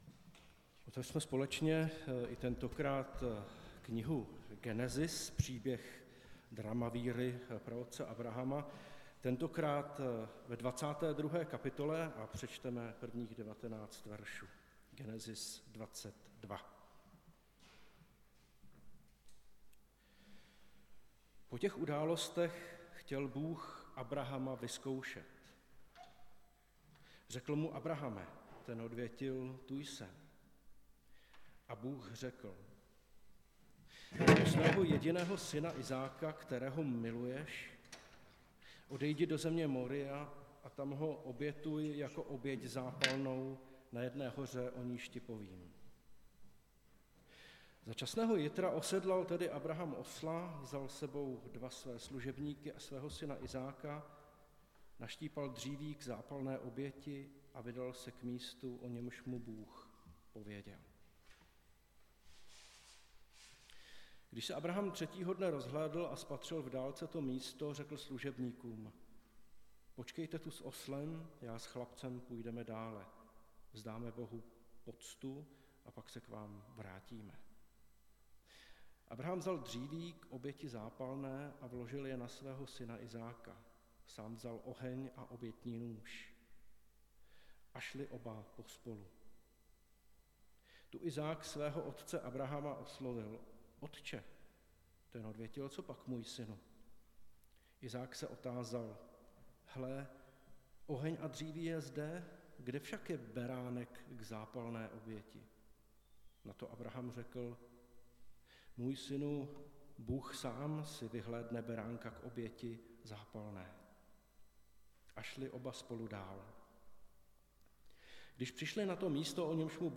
Nedělní kázání – 15.1.2023 Bůh je věrný!